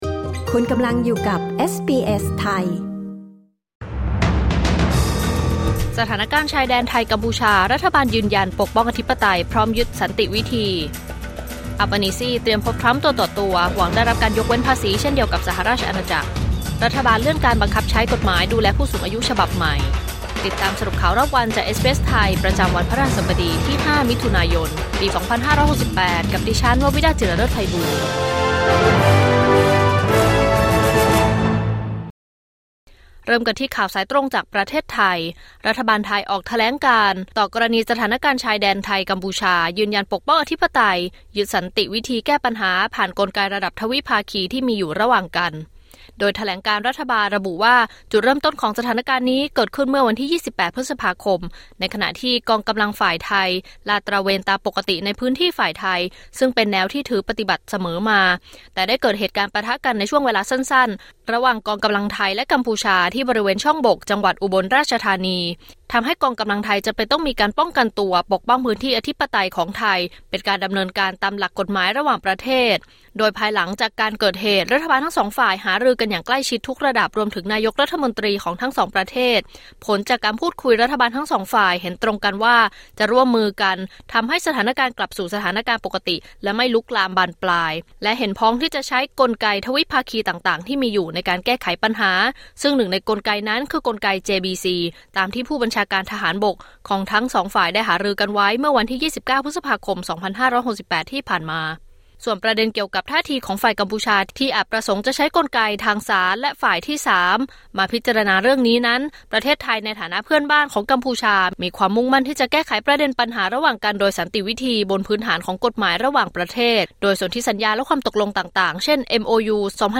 สรุปข่าวรอบวัน 5 มิถุนายน 2568